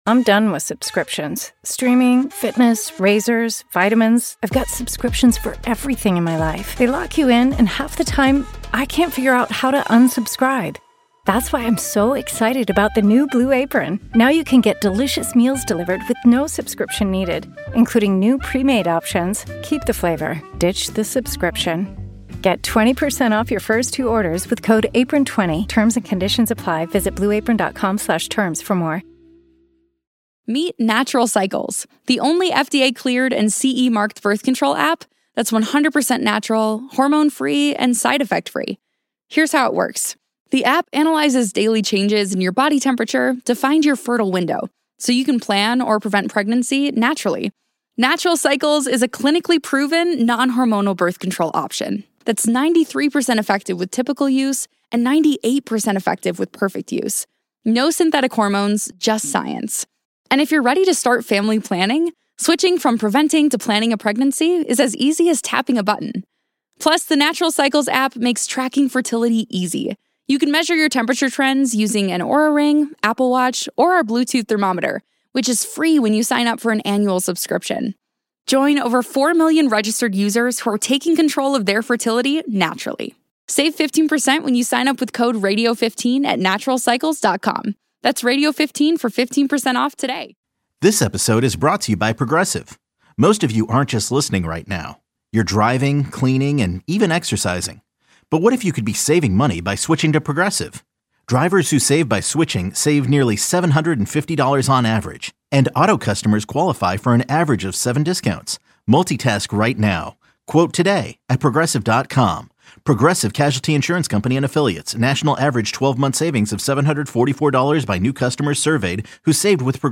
In this conversation, they explore: ✨ What they’ve learned from the rapid rise of generative AI ✨ How educators and system leaders are navigating real implementation…